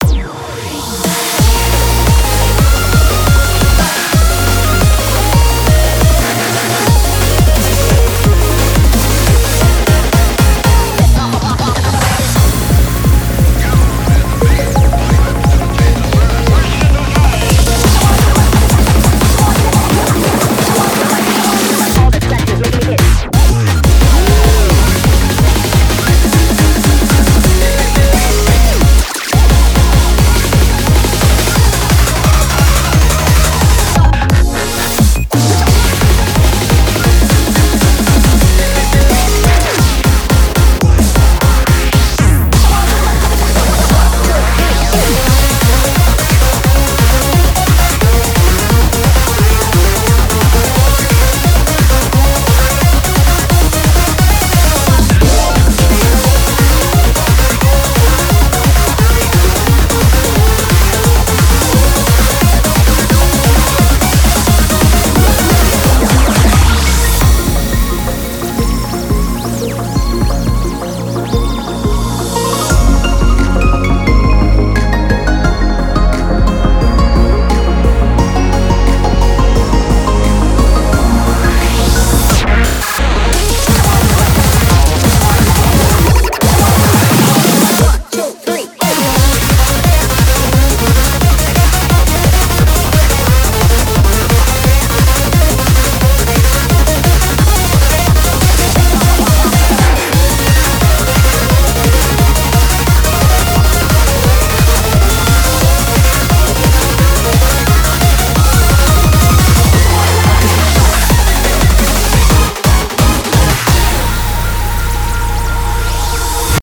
BPM175